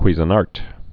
(kwēzə-närt, kwēzə-närt)